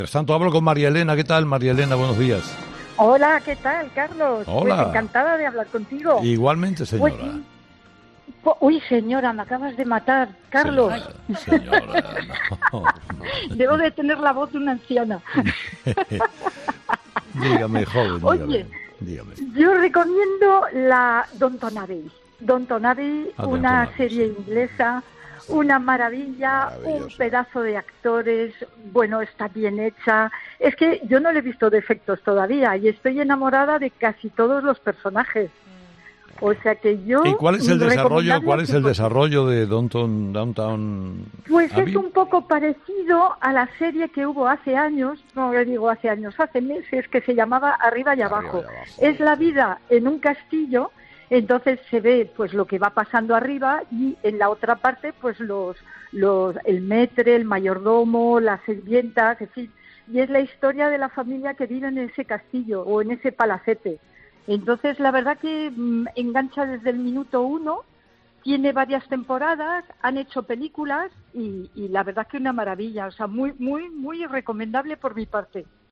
La oyente, en tono de humor, ha bromeado con el director de 'Herrera en COPE' sobre su tono de voz
El 'enfado' con mucho humor en directo de una oyente de COPE por cómo se refiere a ella Carlos Herrera